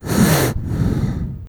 gas_mask_hard_breath3.wav